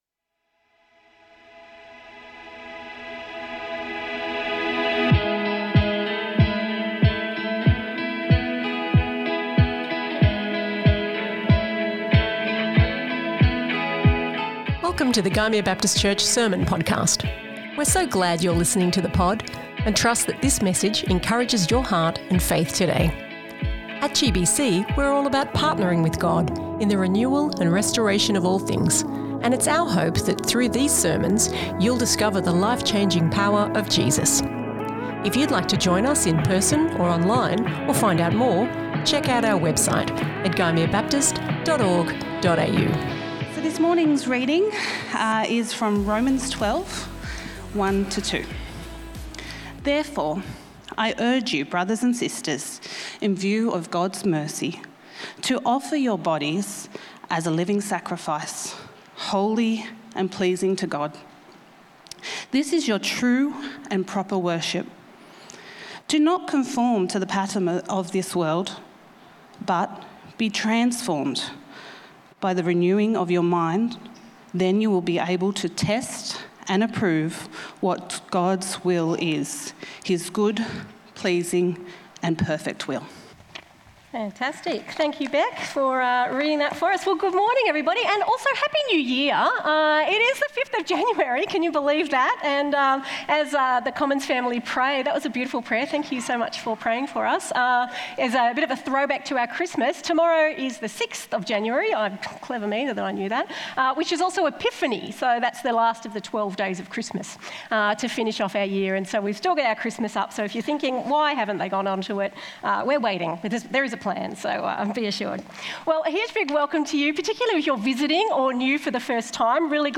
This is the first in our new series, 'Jesus and the Sydney Morning Herald', in which we bring a biblical focus on current news items. Today's sermon looks at the Word of the Year: Brainrot.